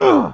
1 channel
Grunt_01.mp3